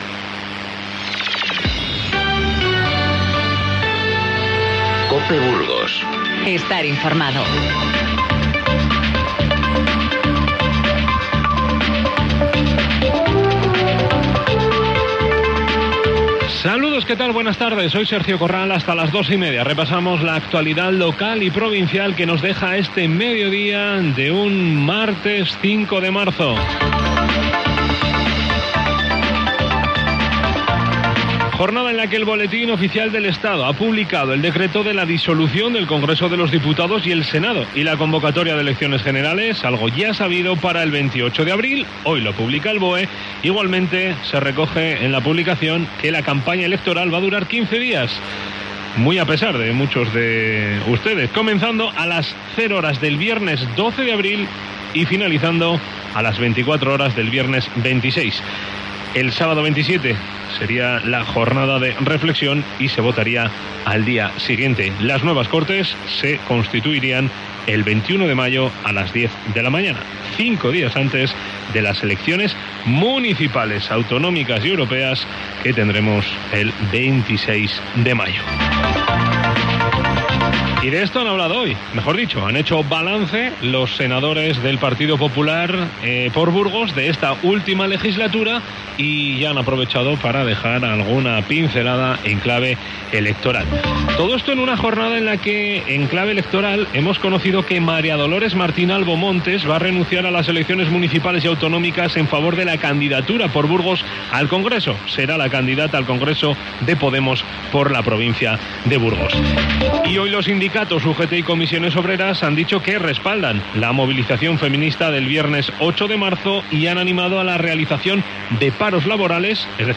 Informativo Mediodía COPE Burgos 05/03/19